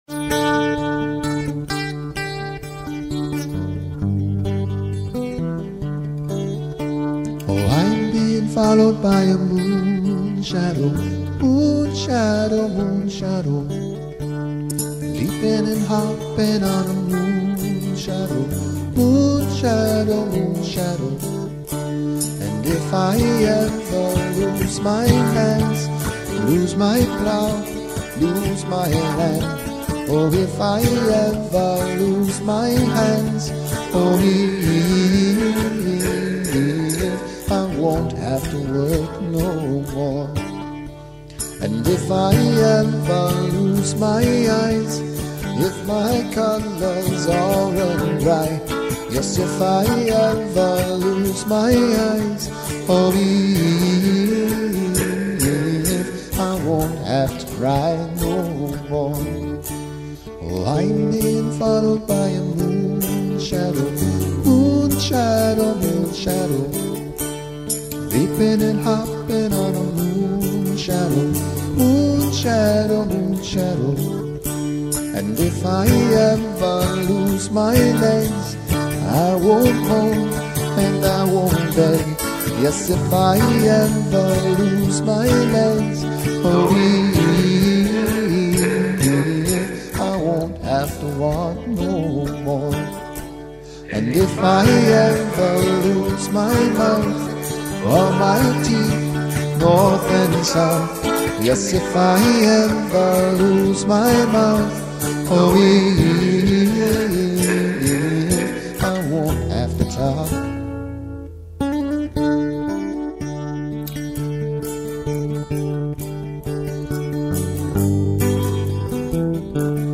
Covers
Acoustic guitars, percussion and backing vocals.
My voice sounded too loud, haha.